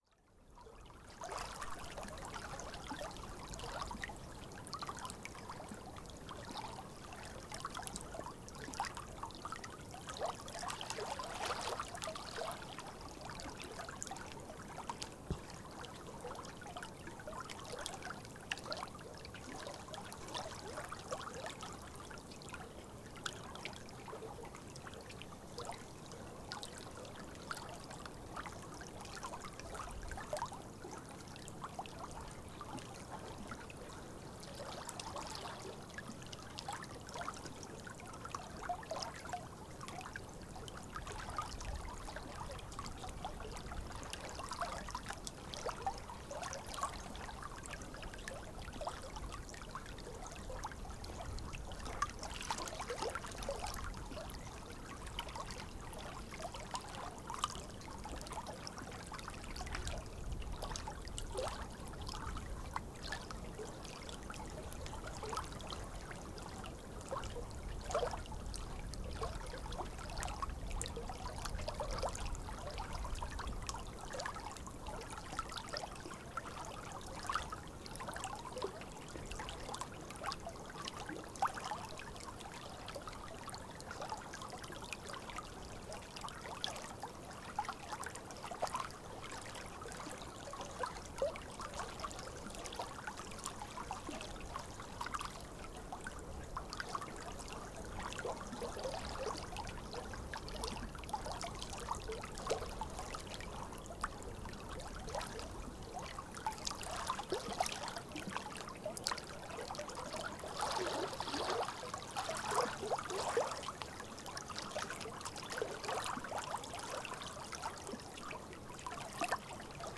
Genre : Soundscapes.